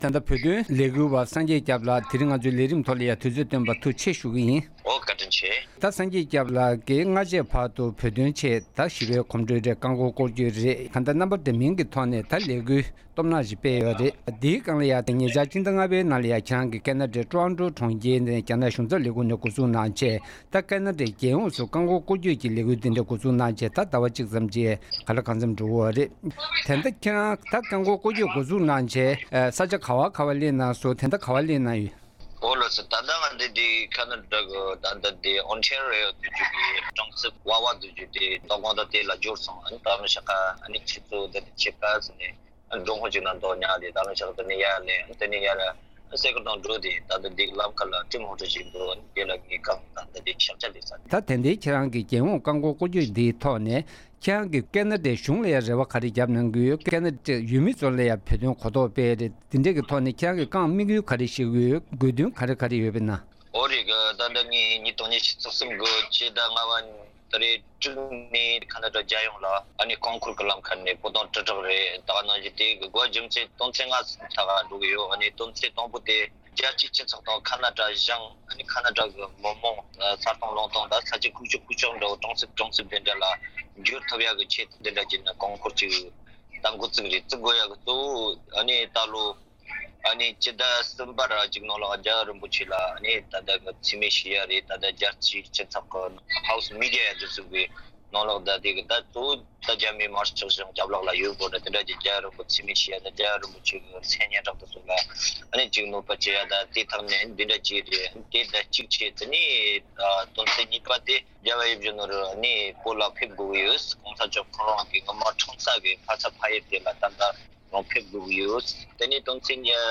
ཁེ་ན་ཌའི་རྒྱལ་ཡོངས་སུ་བོད་དོན་རྐང་འཁོར་སྐོར་བསྐྱོད་ཀྱི་དགོས་དོན་དང་དམིགས་ཡུལ་སྐོར་ངོ་སྤྲོད་གནང་བ།